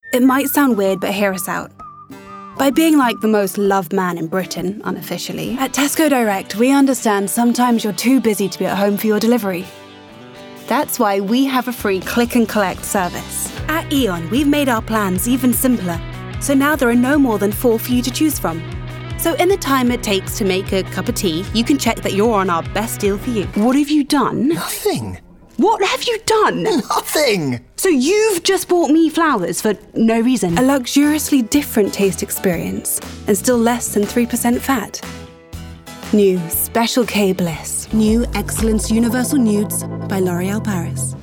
Commercial Reel
RP ('Received Pronunciation')
Commercial, Upbeat, Warm